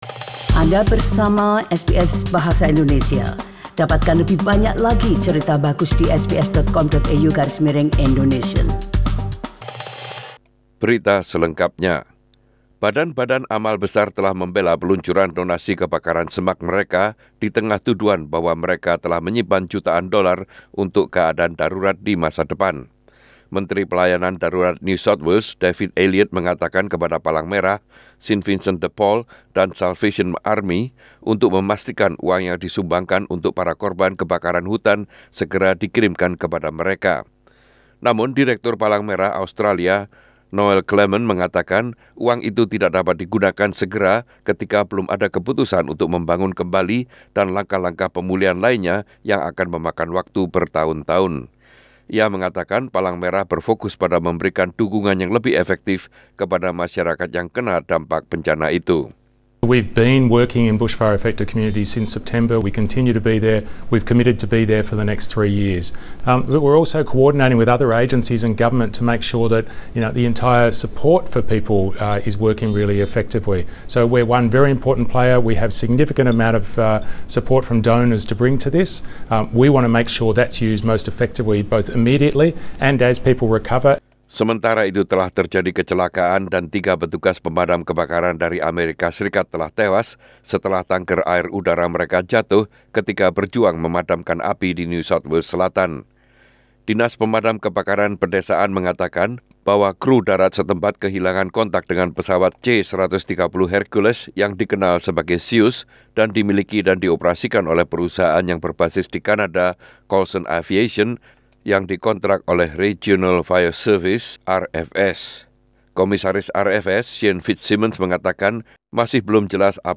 SBS Radio News in Indonesian - 24 Jan 2020